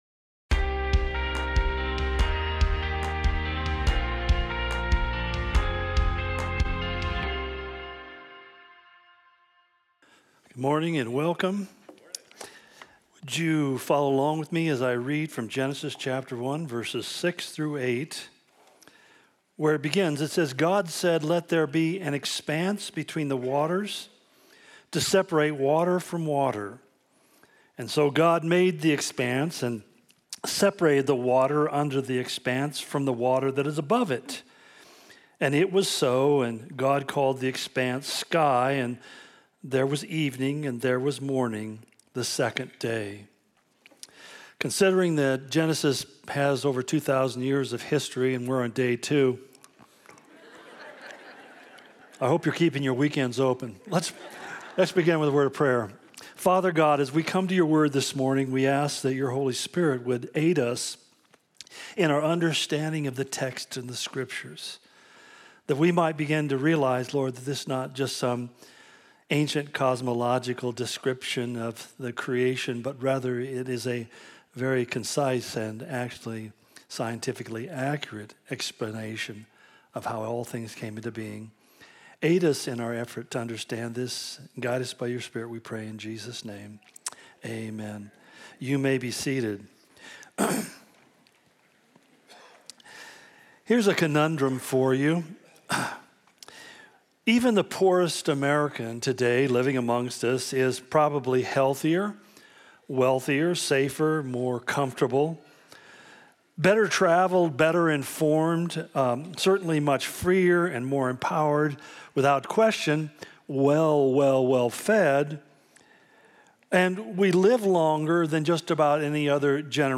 The Origin Of Everything - Part 3 Day Two Calvary Spokane Sermon Of The Week podcast